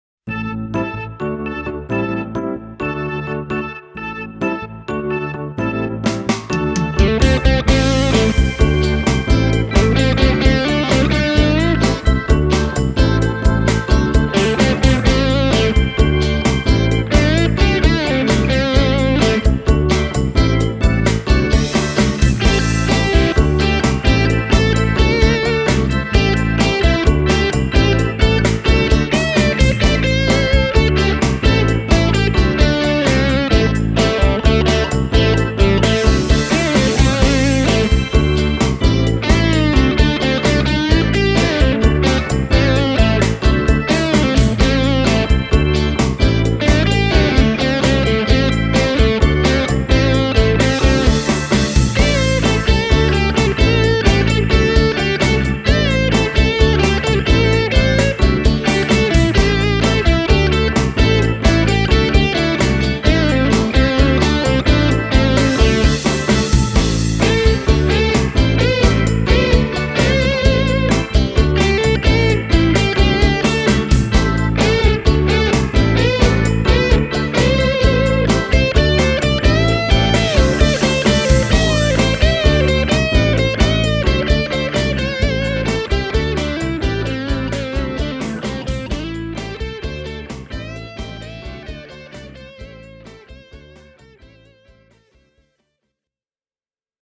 Äänitin siksi demobiisissä kitararaitojen lisäksi myös kosketinosuudet Gurus-pedaalin kautta: